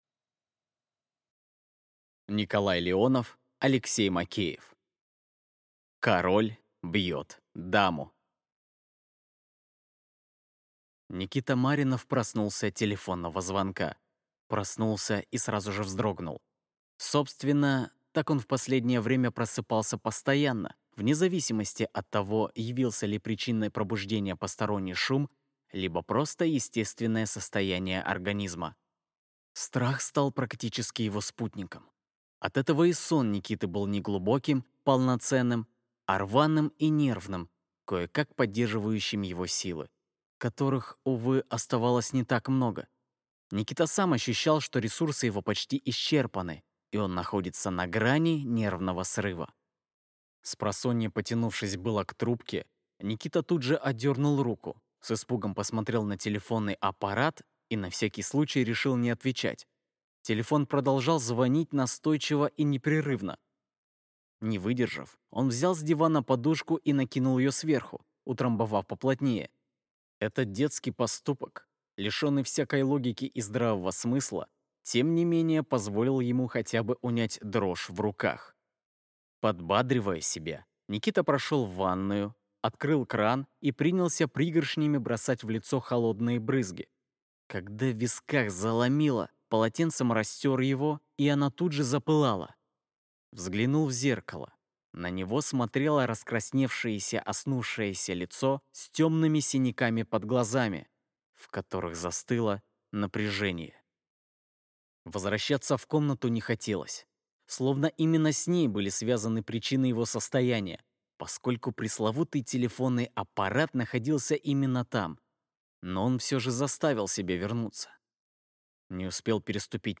Аудиокнига Король бьет даму | Библиотека аудиокниг